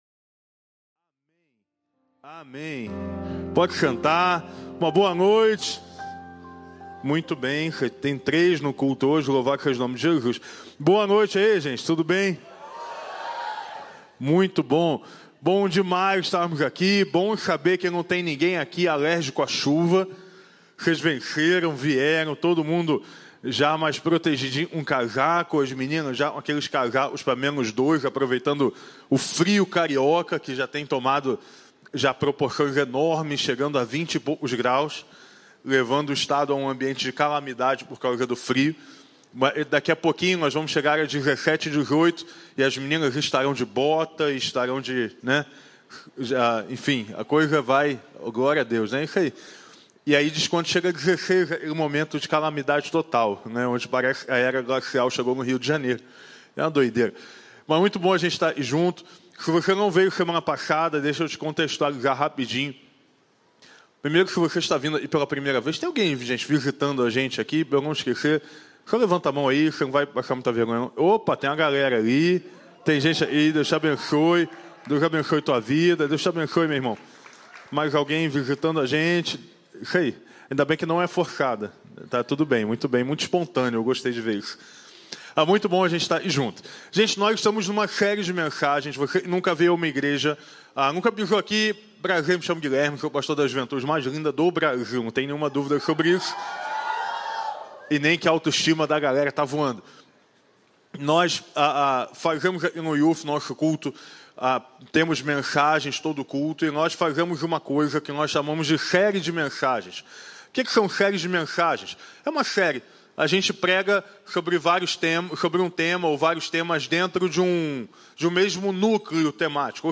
Mensagem
na Igreja Batista do Recreio